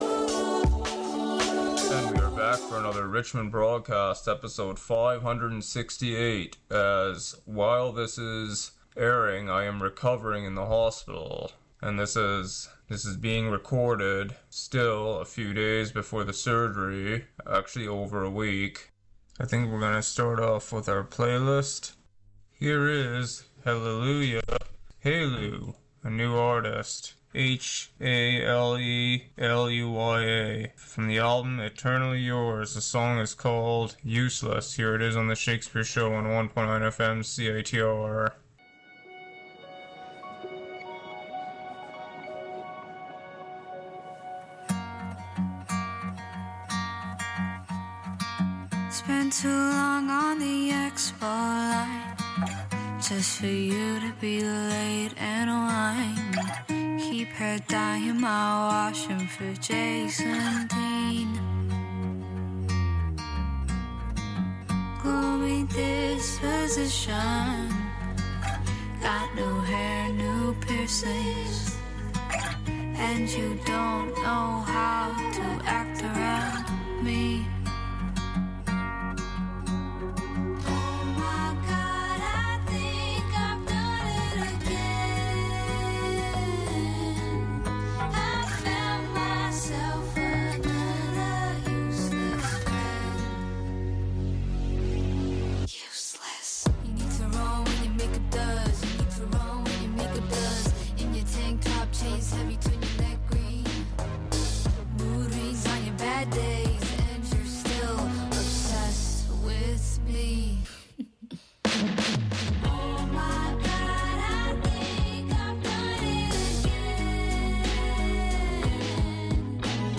an eclectic mix of music